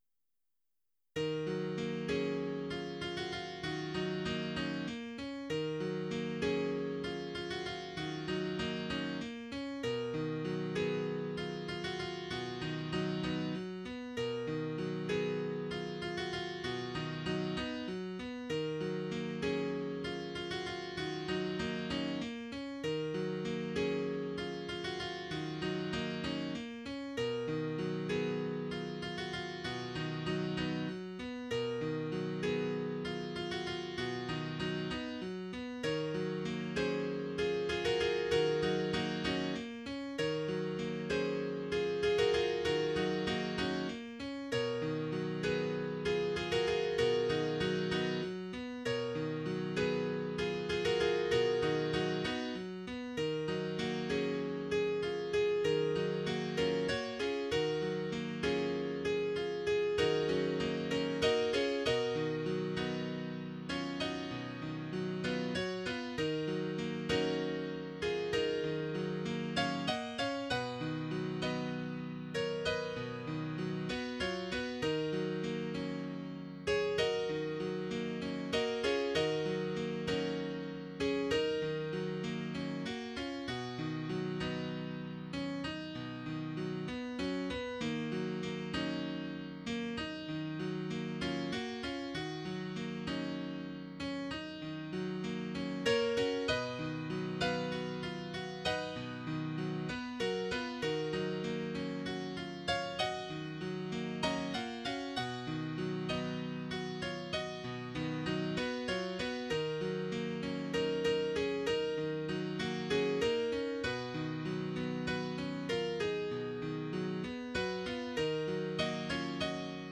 Title Angel Opus # 89 Year 0000 Duration 00:02:44 Self-Rating 4 Description Another of man's attempts to describe beauty through music. For ease of play, this sounds fine in A minor; transpose down 6 half-steps. The computer playback is far more rigid than is my intention. Tempo should be by feel. mp3 download wav download Files: mp3 wav Tags: Solo, Piano Plays: 1424 Likes: 0